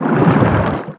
Sfx_creature_pinnacarid_swim_slow_01.ogg